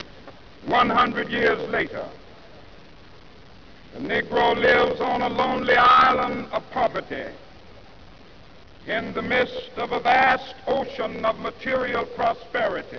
Dr. Martin Luther King, Jr. Aug. 28, 1963
I am happy to join with you today in what will go down in history as the greatest demonstration for freedom in the history of our nation.